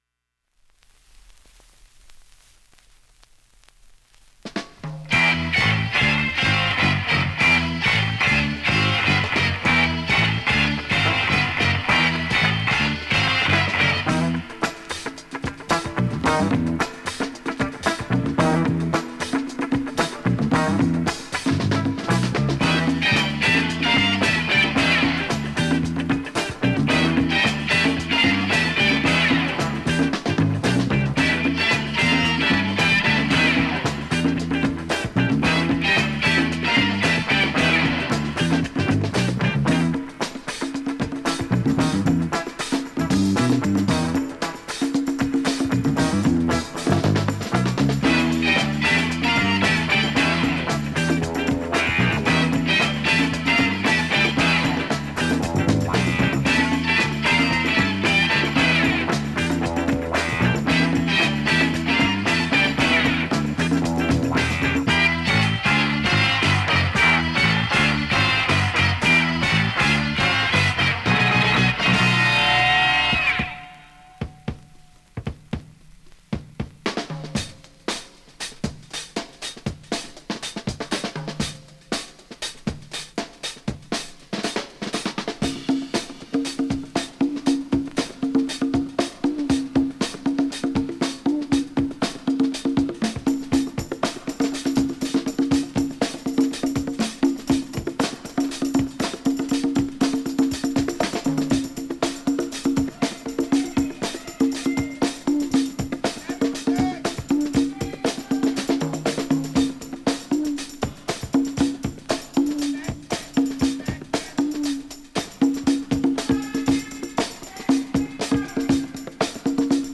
現物の試聴（両面すべて録音時間7分55秒）できます。
様々なアーティストにサンプリングされた ファンククラシック